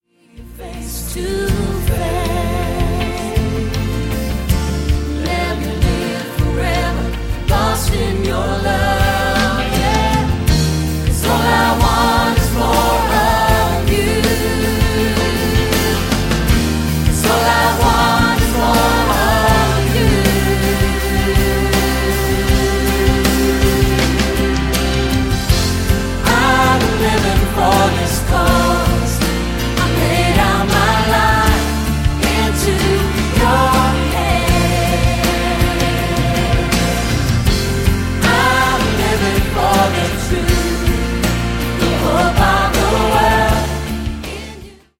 Lobpreis